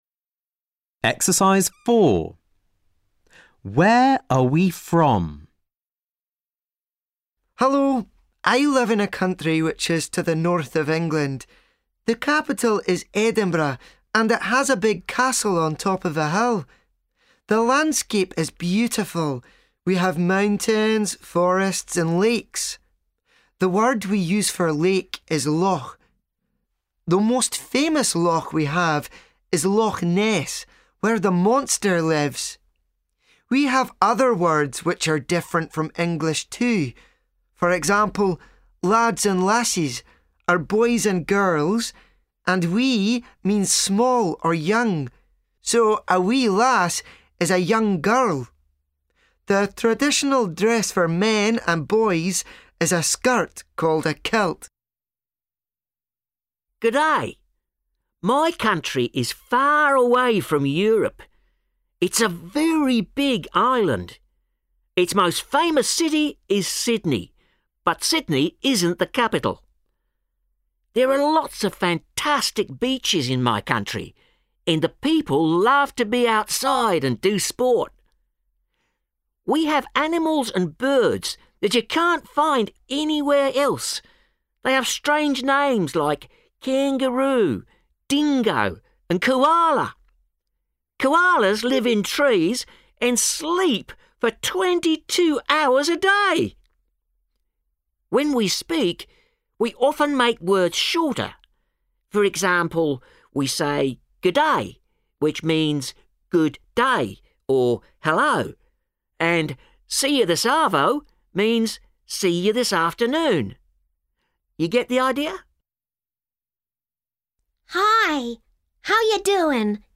• Speaker 1: Scotland
• Speaker 2: Australia
• Speaker 3: USA
• Speaker 4: Ireland
• Speaker 5: Scotland